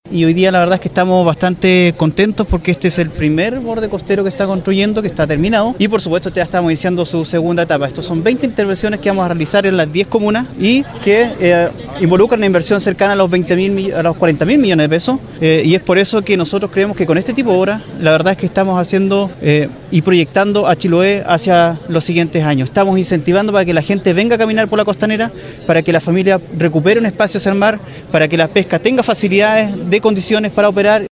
El seremi de Obras Públicas Carlos Contreras recalcó en la ocasión que se está cumpliendo con el plan trazado en orden a modernizar el Borde Costero en puntos estratégicos de 10 comunas de Chiloé.